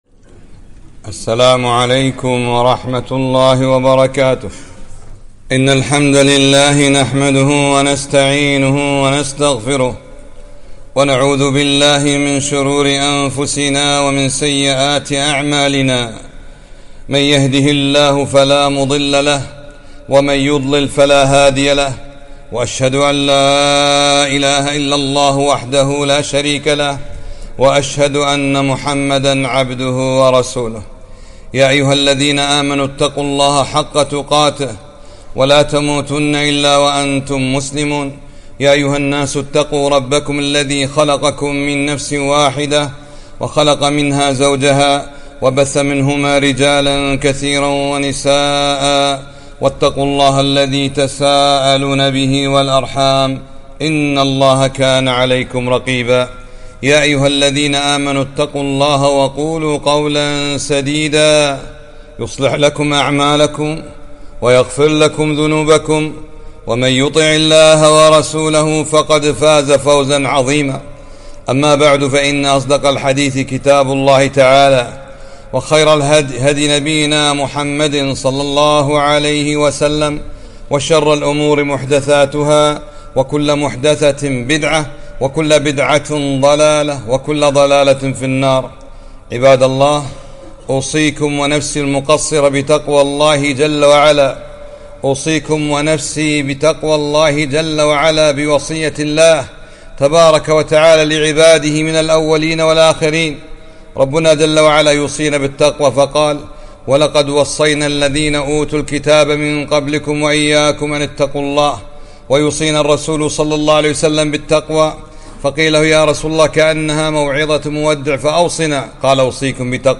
خطبة - الجواب على السؤال الشيطاني من خلق الله